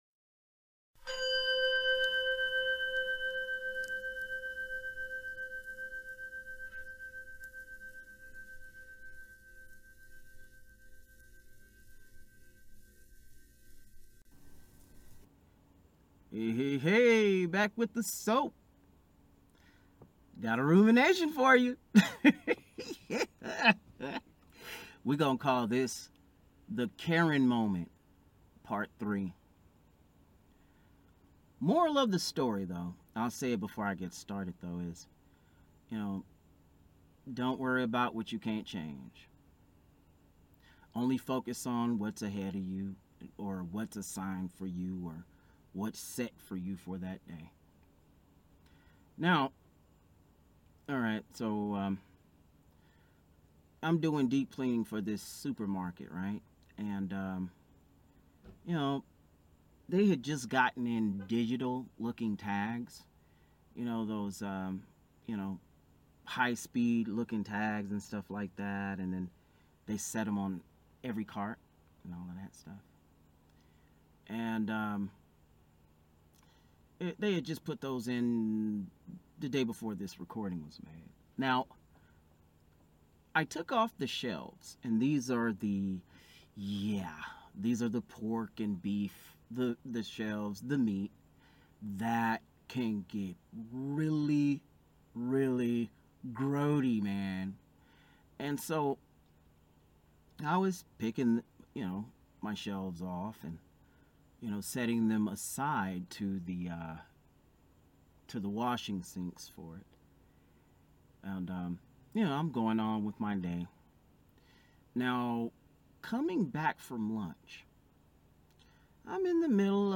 The Audio is rather low....